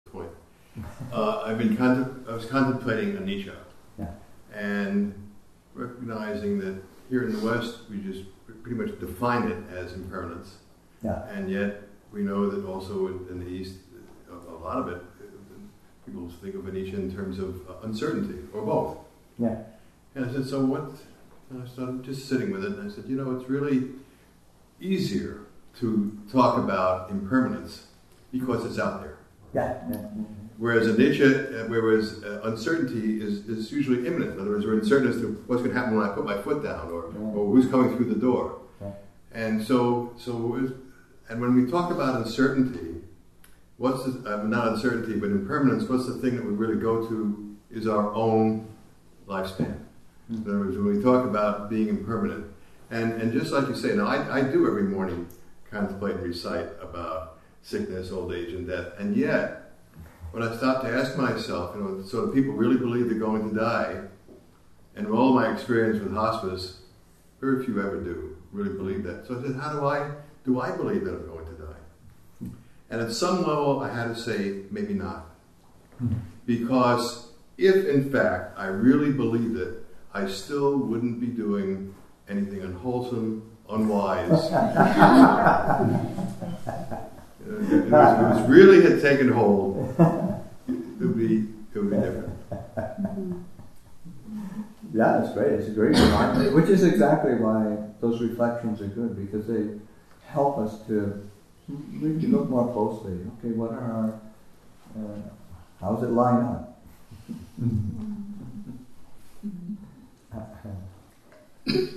Death and Dying [2014], Session 1, Excerpt 12